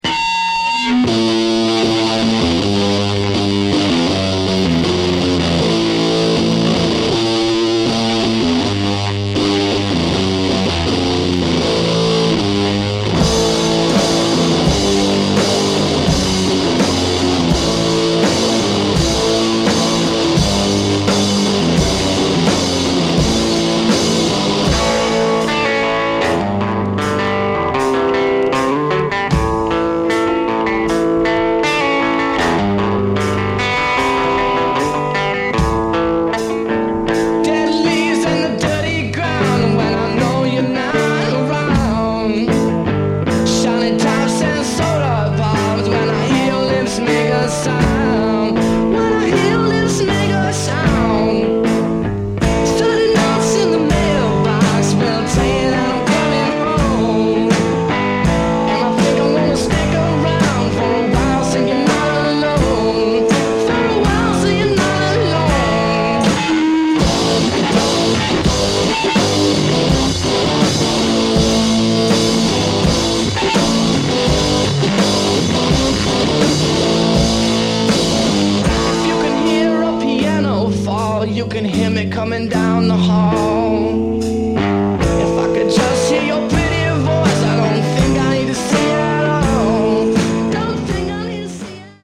Live At The BBC Studios, Maida Vale